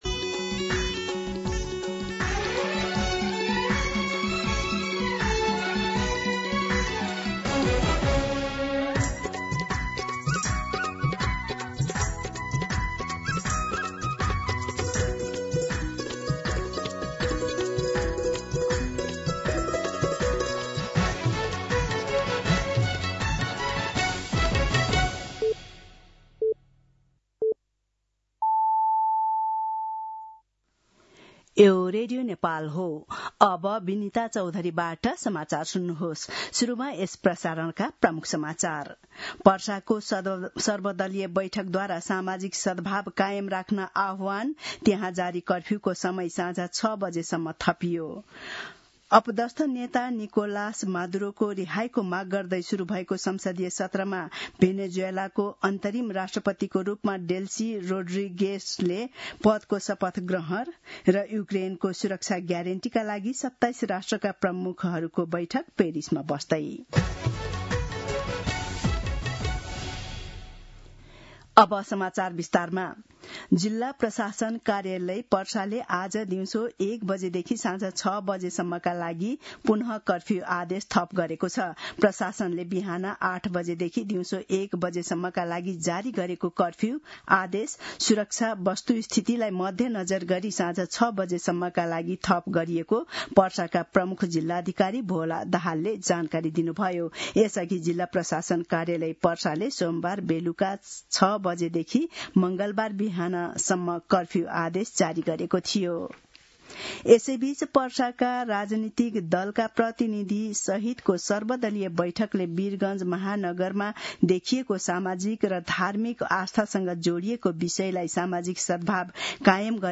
दिउँसो ३ बजेको नेपाली समाचार : २२ पुष , २०८२
3pm-News-09-22.mp3